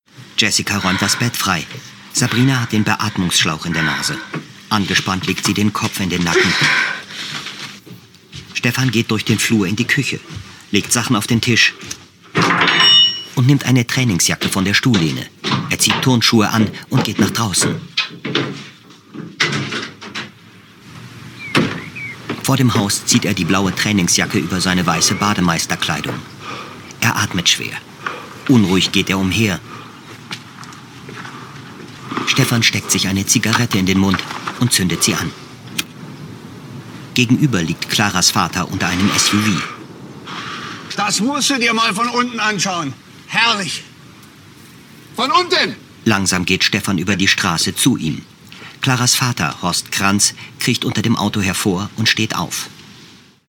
AD_Demo_Nuechtern.mp3